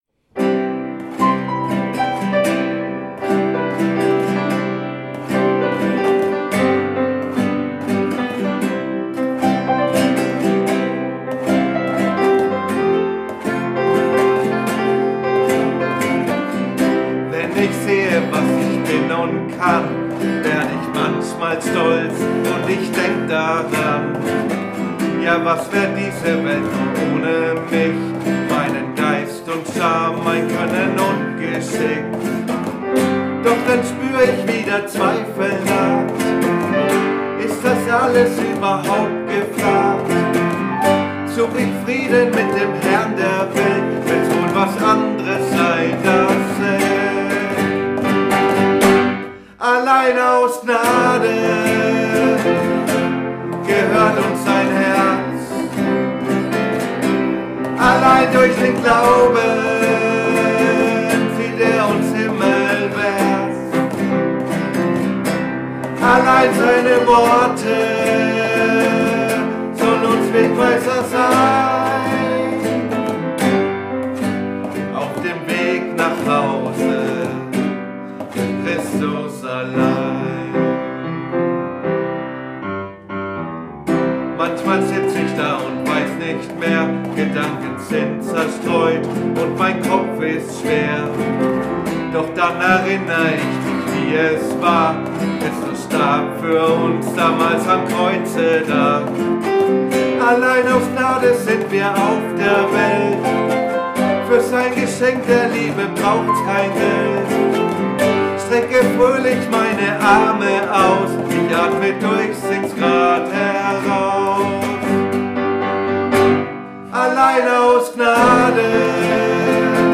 Gesang, Gitarre
Klavier